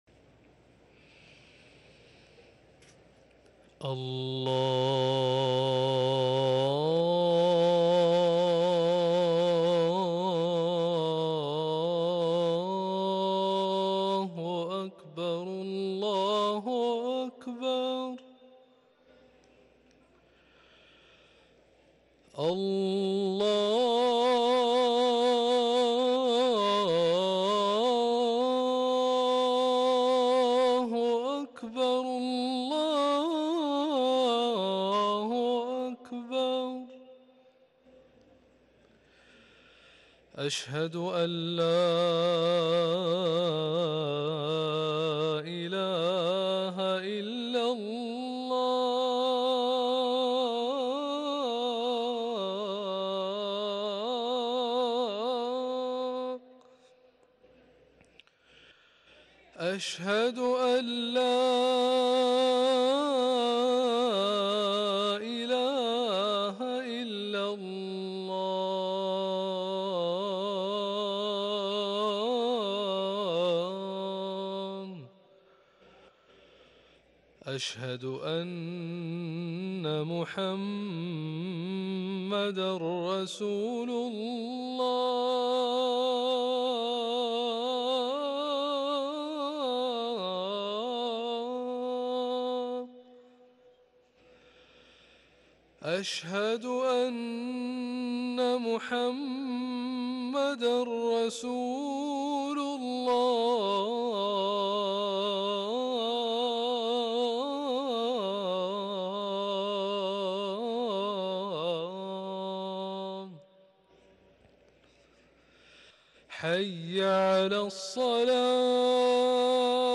اذان العشاء